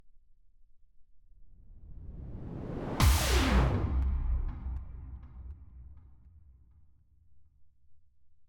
whoosh sci fi